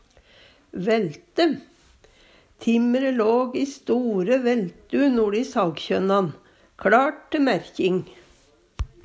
vælte - Numedalsmål (en-US)